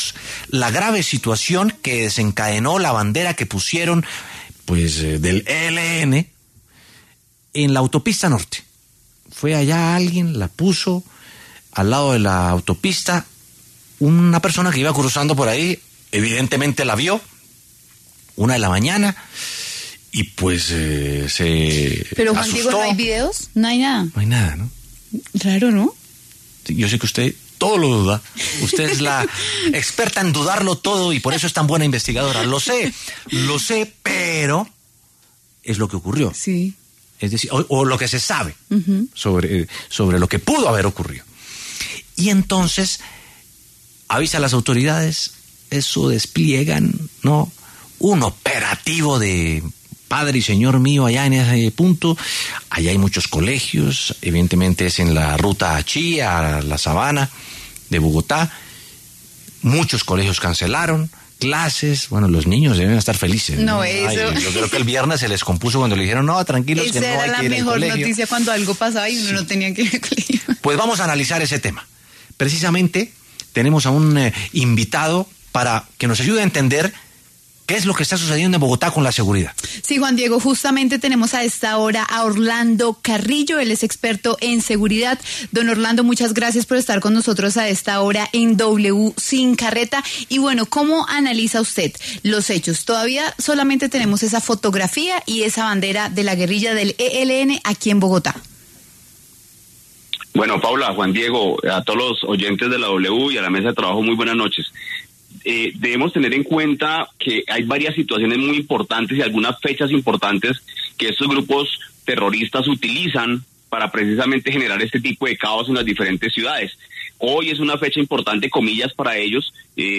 experto en seguridad